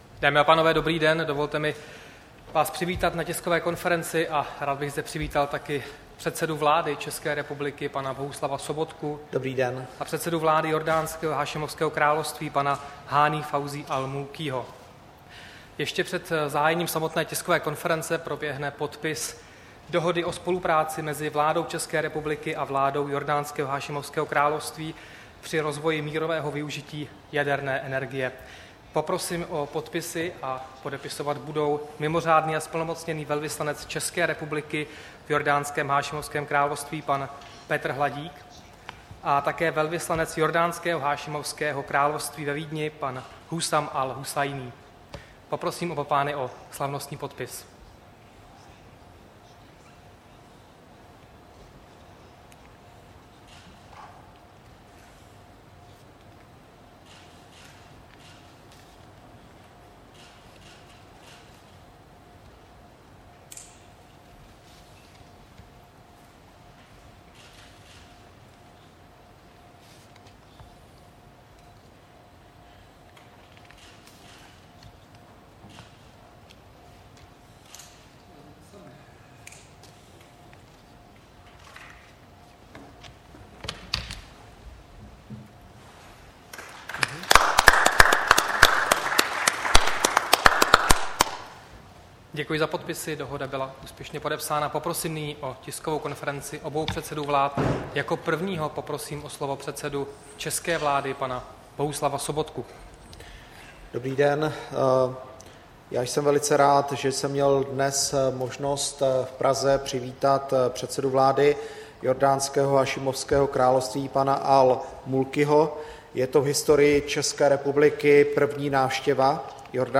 Tisková konference po jednání premiéra Bohuslava Sobotky s premiérem Jordánska Hani Fawzi Al-Mulkim, 22. srpna 2017